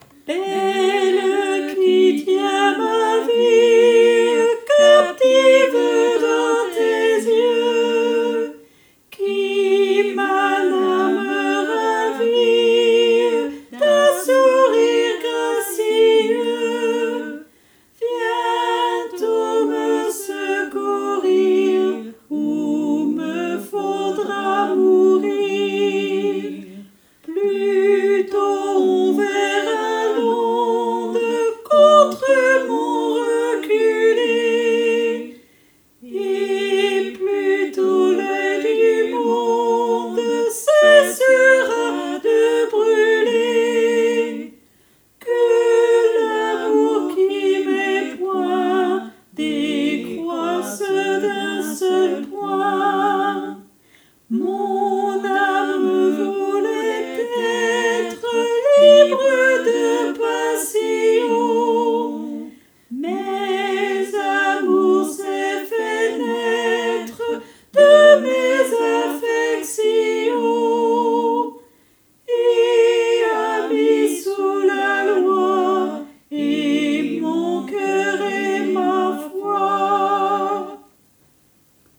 Soprano et autres voix en arrière-plan